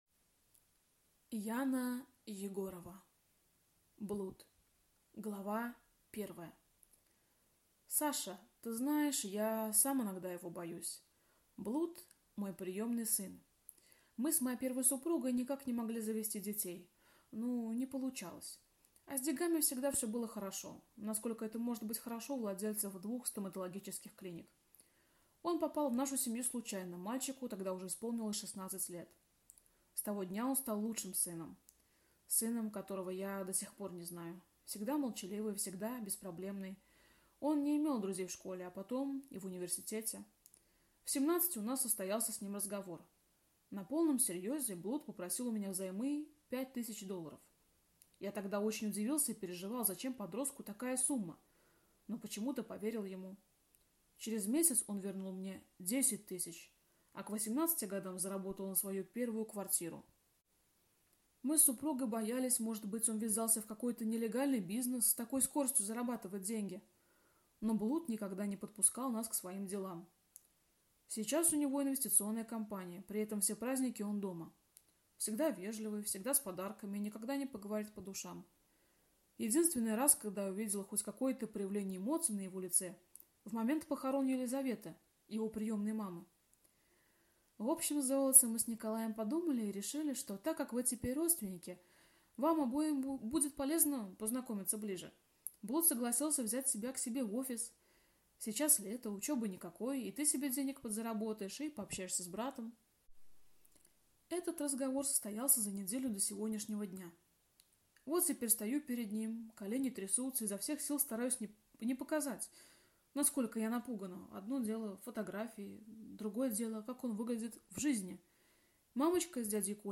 Аудиокнига Блуд | Библиотека аудиокниг